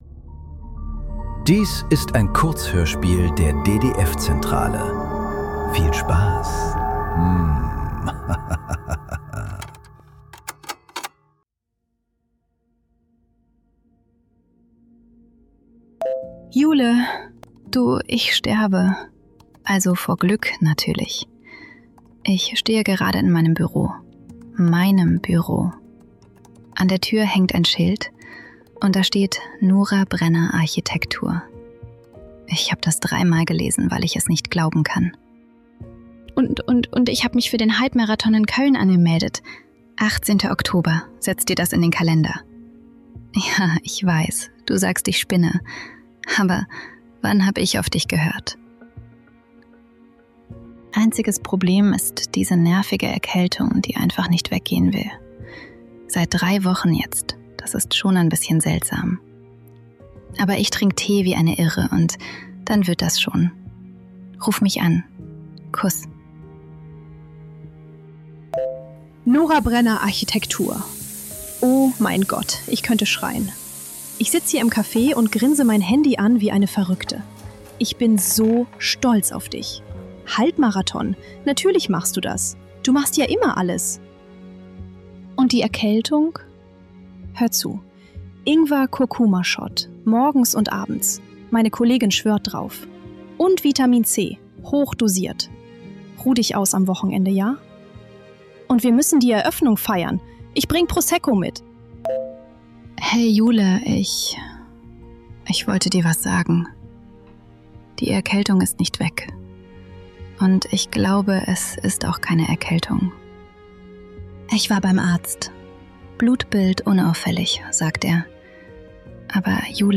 47 Entwürfe ~ Nachklang. Kurzhörspiele.